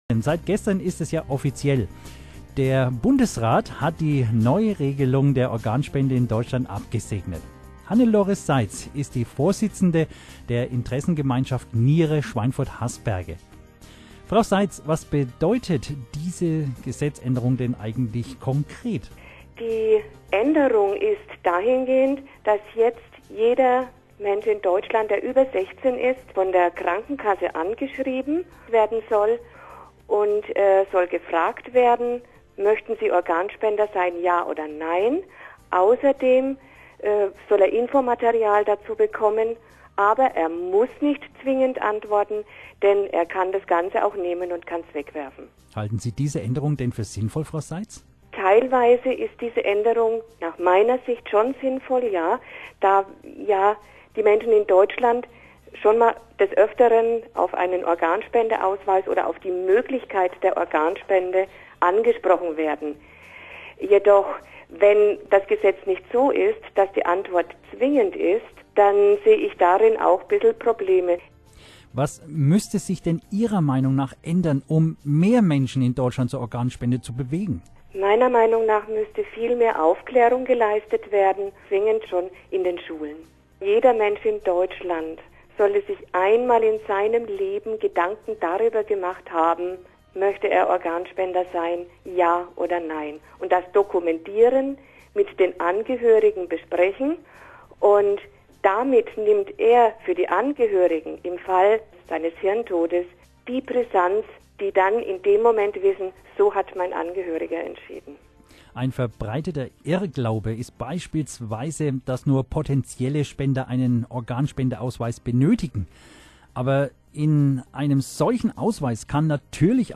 Interview zur Zustimmung des Bundesrates zum neuen Transplantationsgesetz